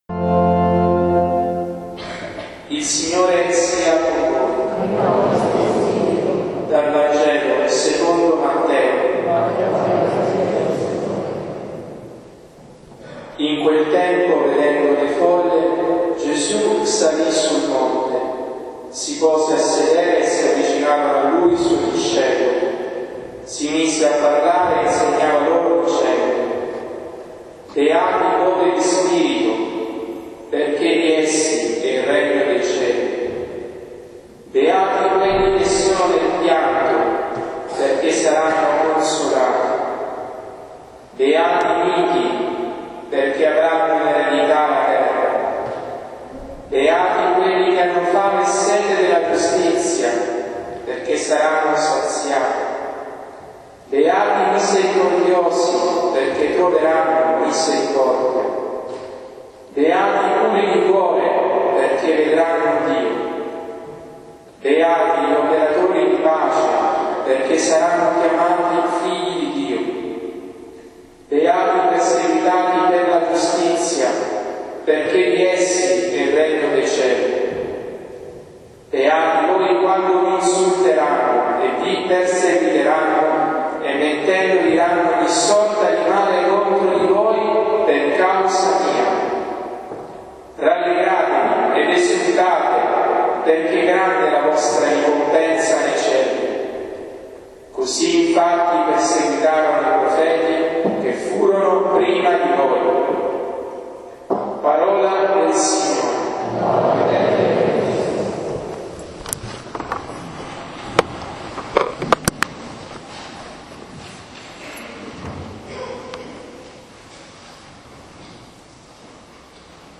Commento alle Letture della Santa Messa ed esortazione ai fedeli